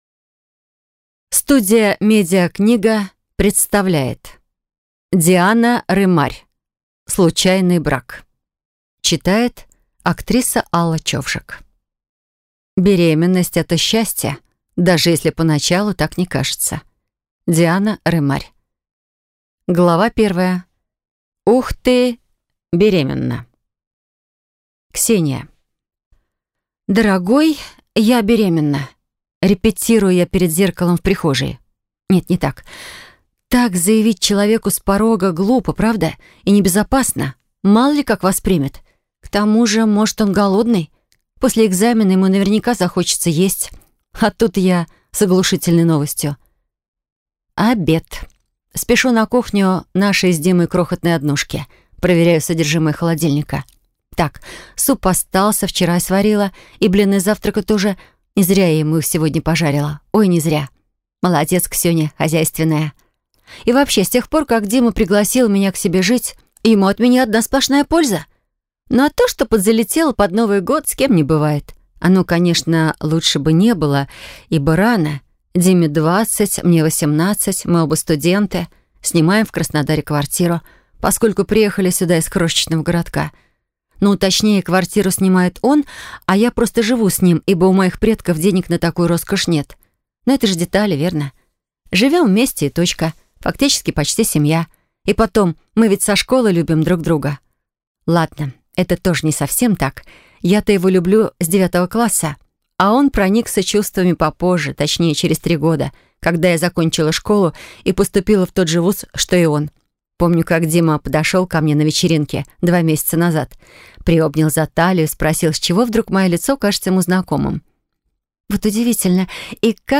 Аудиокнига Случайный брак | Библиотека аудиокниг
Прослушать и бесплатно скачать фрагмент аудиокниги